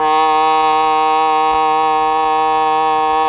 RED.OBOE   9.wav